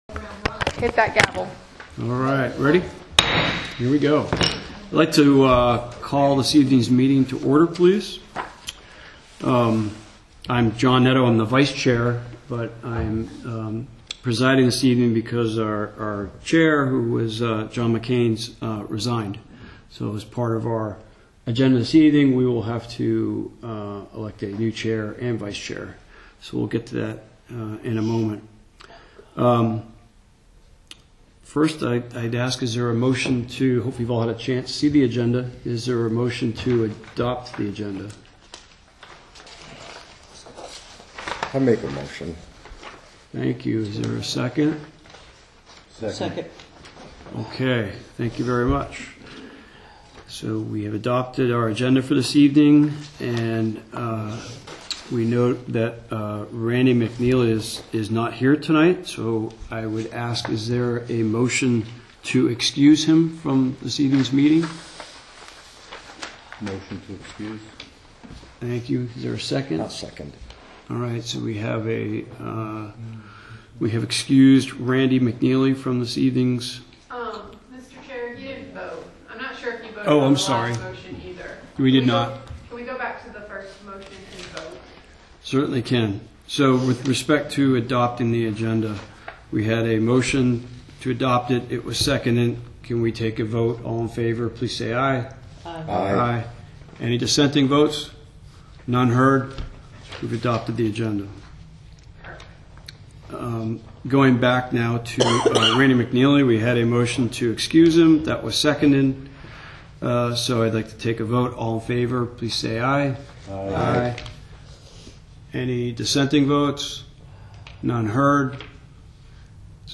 2025-06-09_budget_hearing.WMA